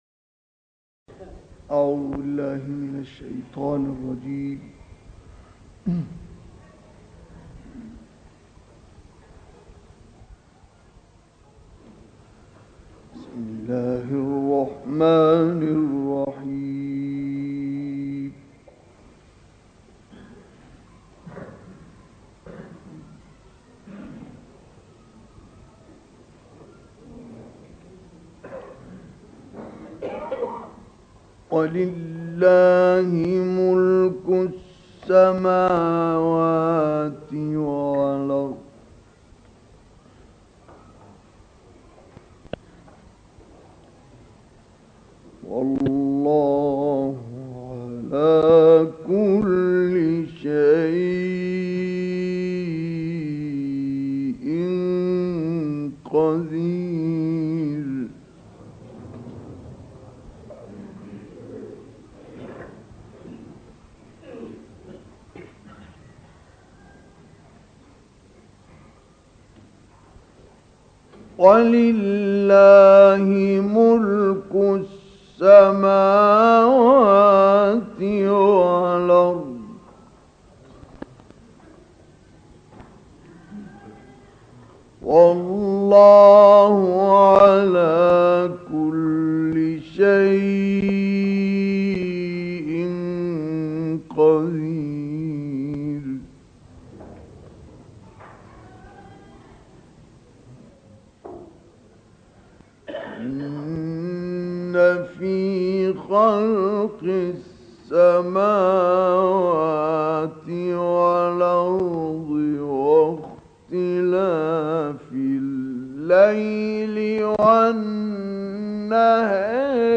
گروه فعالیت‌های قرآنی: عکس و تلاوتی از اکبرالقراء، مصطفی اسماعیل در شهر حمص سوریه در دهه پنجاه میلادی منتشر شد.
در این فایل صوتی مصطفی اسماعیل به تلاوت سوره‌های آل‌عمران آیات 189 تا 195 ، ضحی، شرح، تین و توحید می‌پردازد.